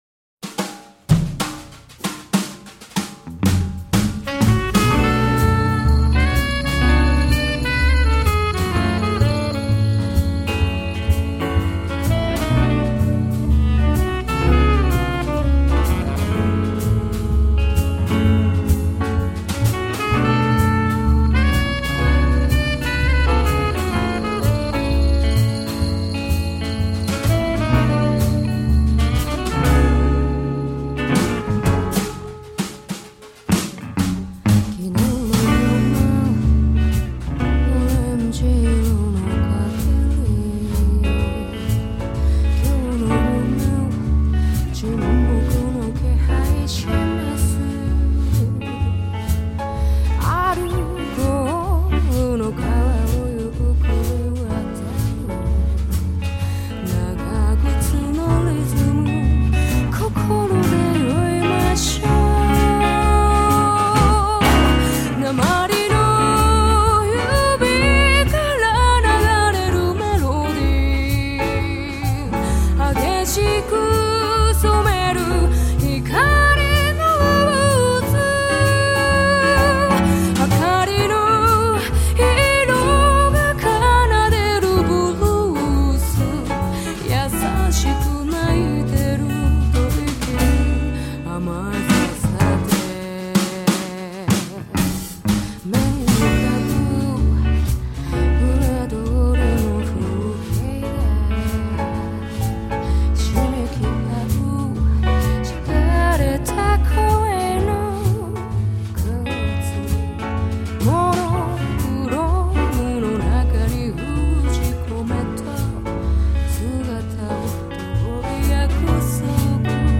Vocal/ songs and lyrics
Guitar/songs